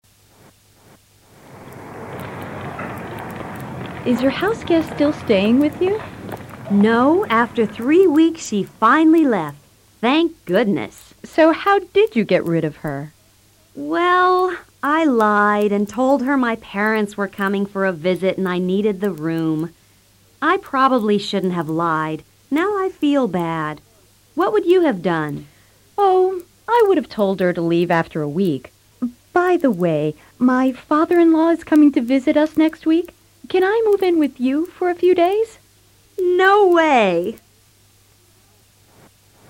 Muriel le cuenta a Lilian acerca de una huésped que estuvo viviendo en su casa. Escucha con atención y repite luego el diálogo.